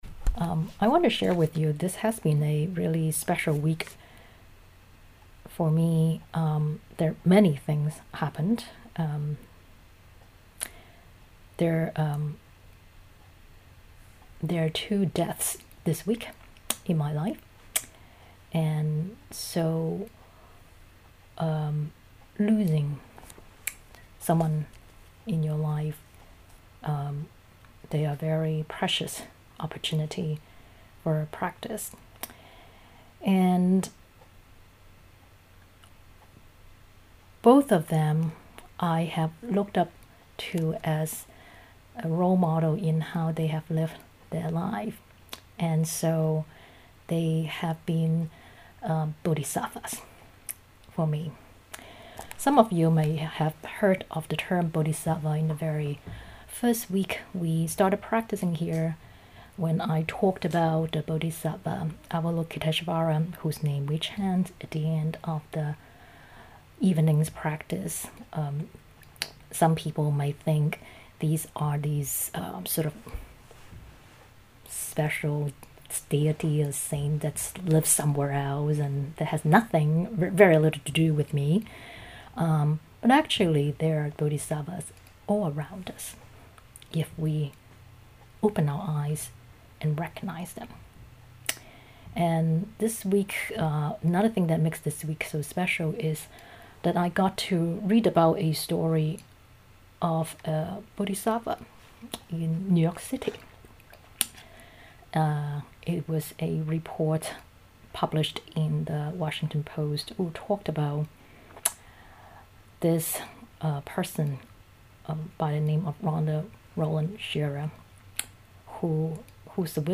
This talk was given in the weekly online Dharma practice gathering on May 8, 2020.